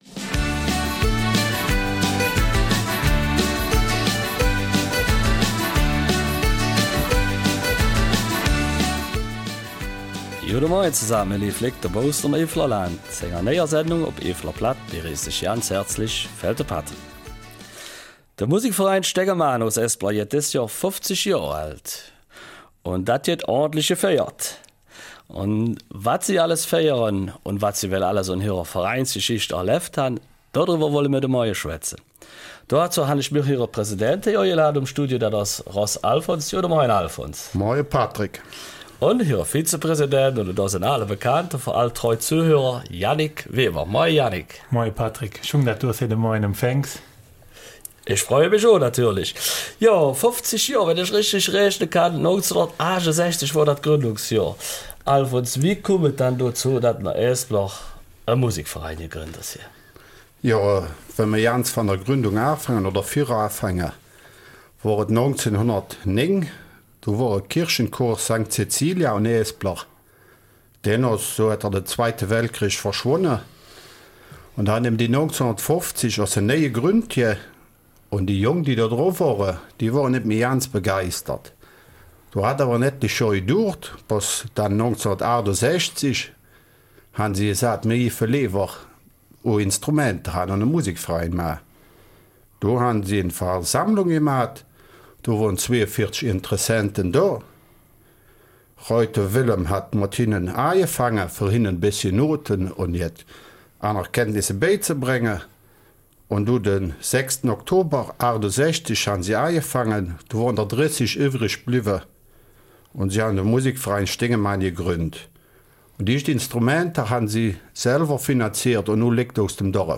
Eifeler Mundart: 50 Jahre Musikverein Steinemann Espeler